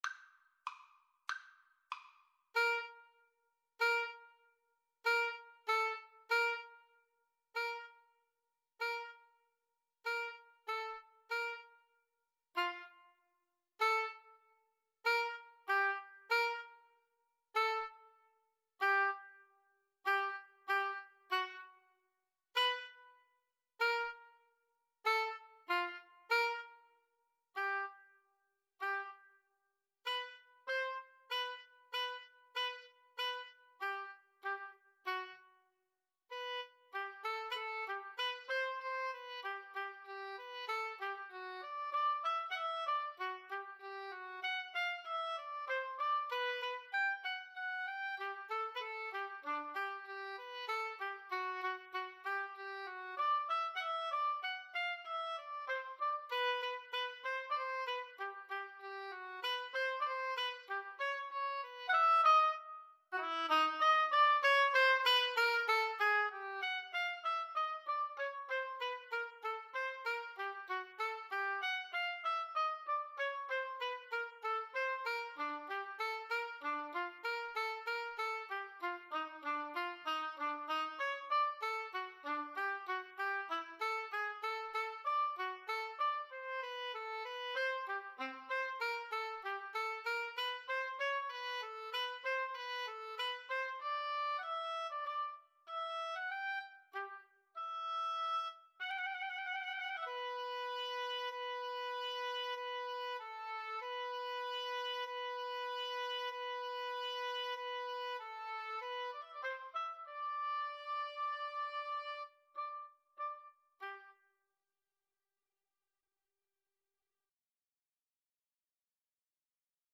2/4 (View more 2/4 Music)
Allegro moderato =96 (View more music marked Allegro)
Oboe Duet  (View more Intermediate Oboe Duet Music)
Classical (View more Classical Oboe Duet Music)